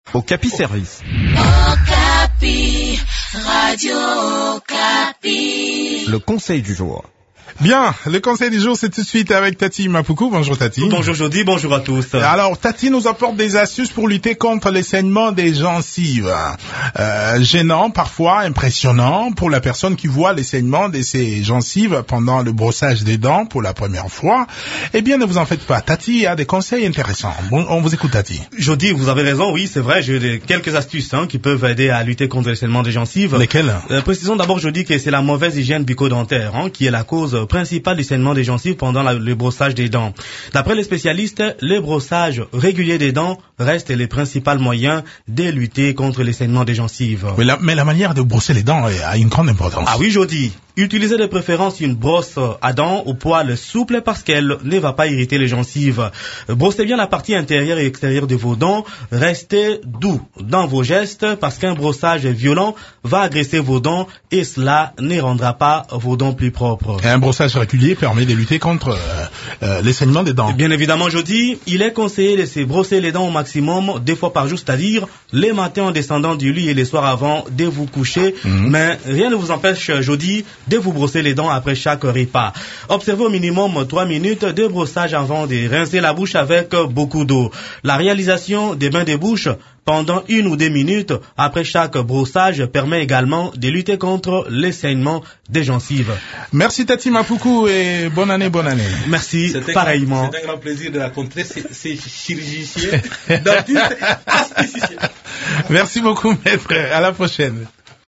Découvrez quelques conseils pour les réduire et, à long terme, les voir disparaître dans cette chronique